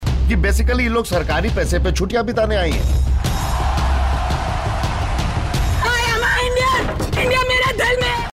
Bollywood Dialogue Tones